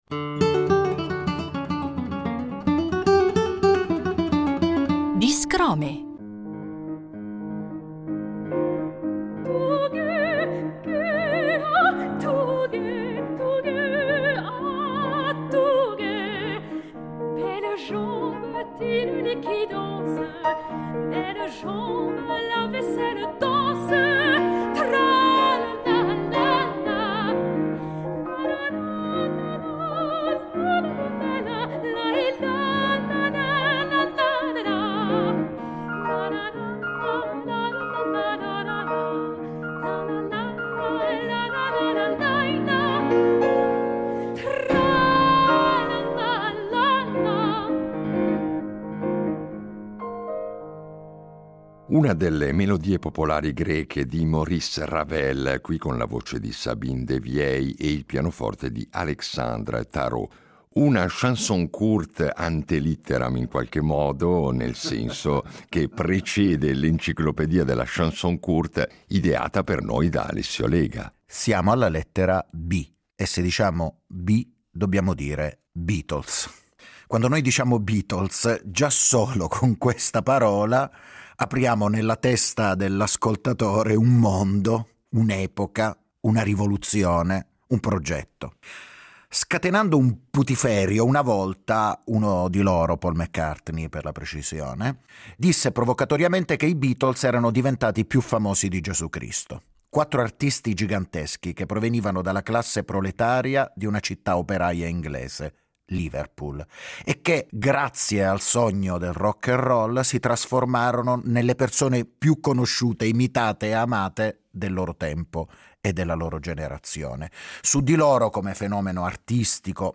Vi facciamo ascoltare queste canzoni, in versione discografica o live, raccontandovi qualcosa dei loro autori e del contesto in cui nacquero… poesia, musica e storie in cinque minuti: un buon affare!